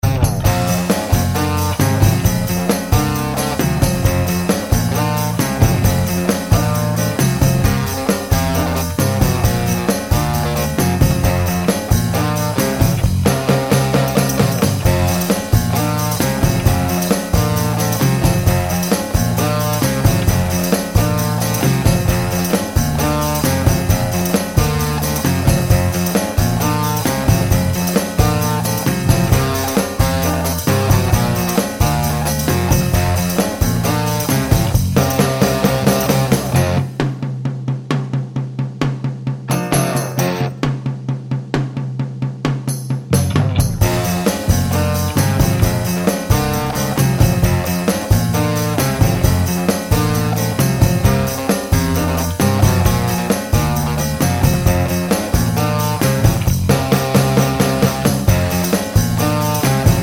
no Backing Vocals Soul